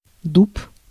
Ääntäminen
RP : IPA : /əʊk/ US : IPA : [əʊk] GenAm: IPA : /oʊk/